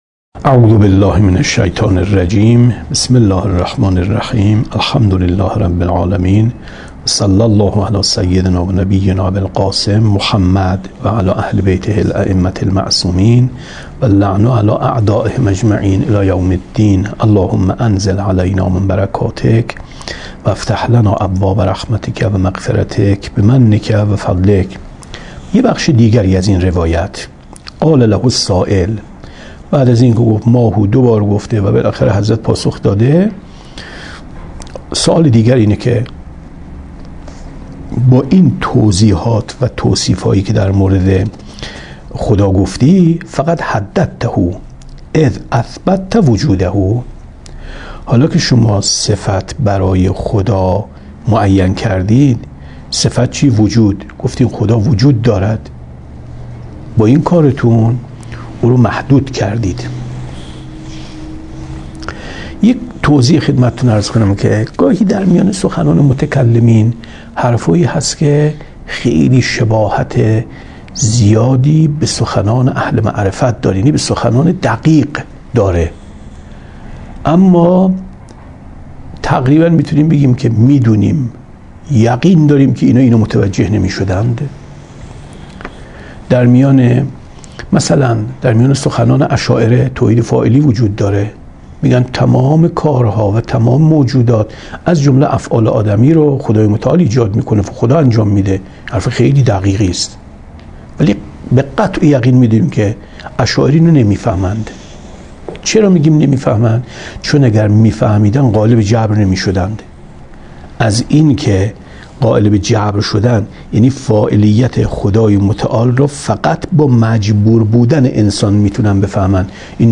کتاب توحید ـ درس 24 ـ 17/ 7/ 95